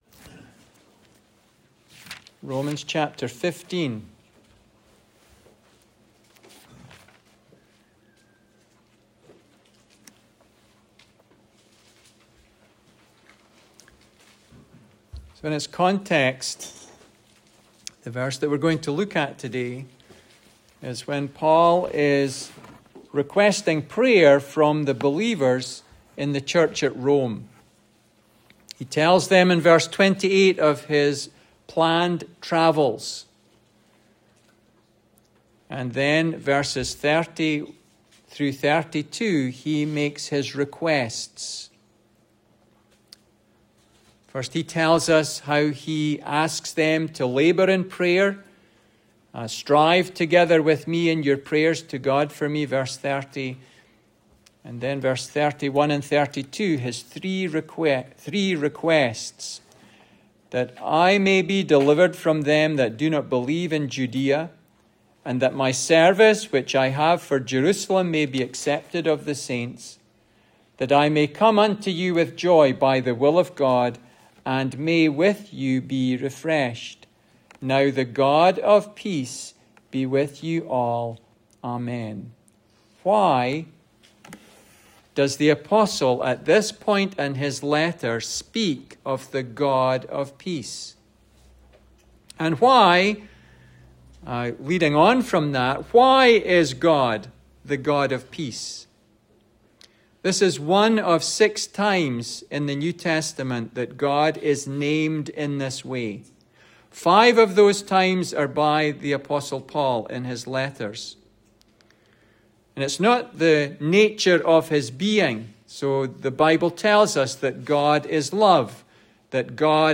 Passage: Romans 15:30-32 Service Type: Sunday Service